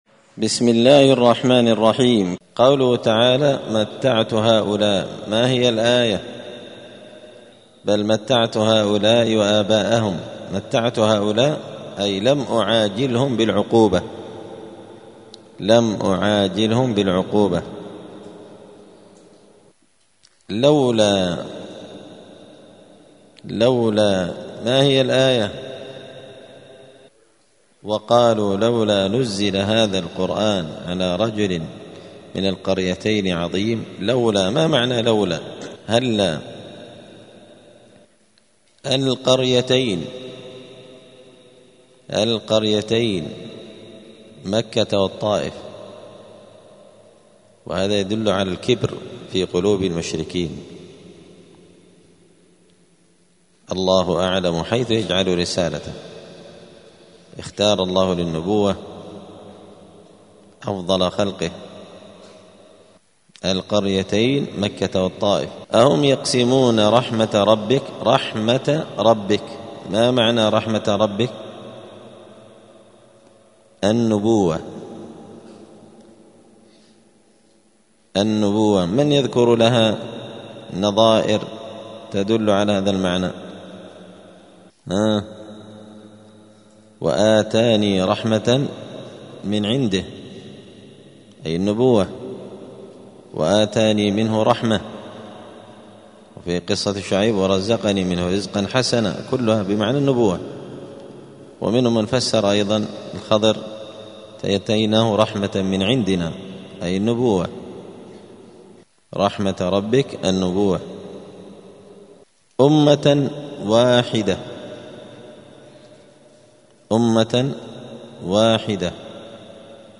زبدة الأقوال في غريب كلام المتعال الدرس السادس والثلاثون بعد المائتين (236)